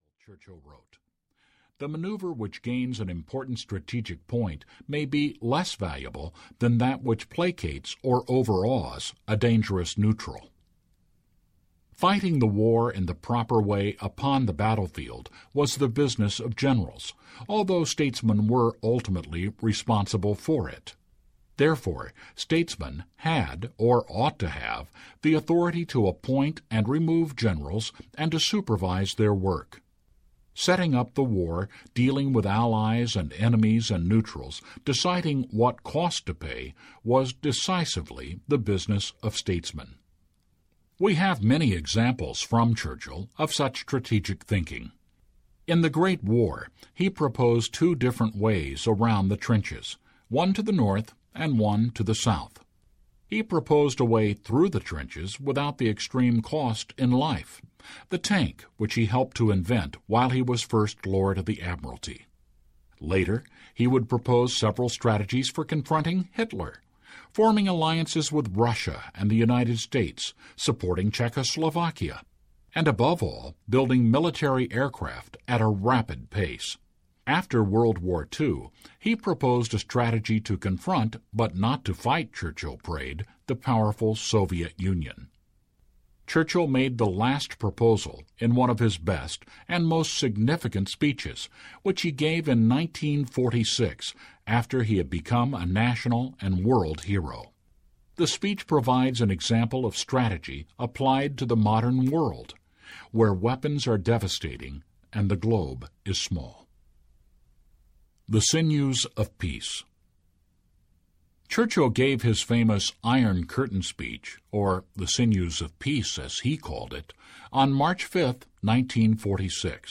Churchill’s Trial Audiobook
Narrator
10.37 Hrs. – Unabridged